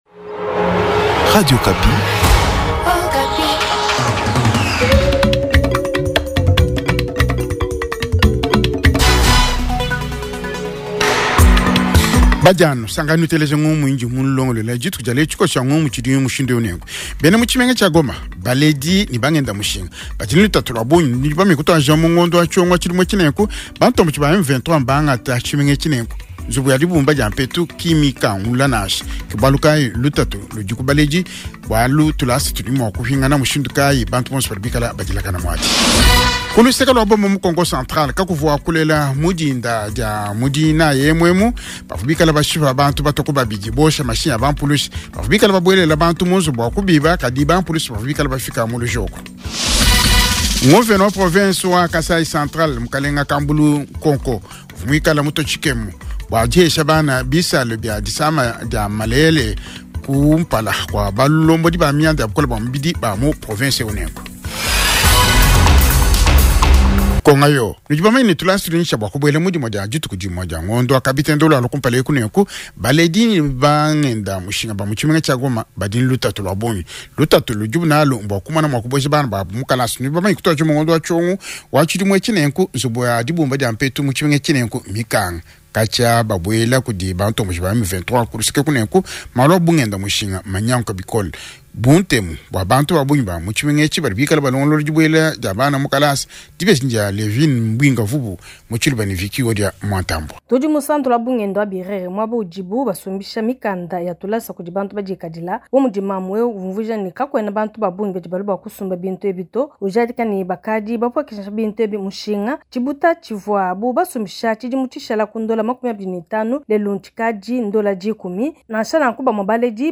Journal Tshiluba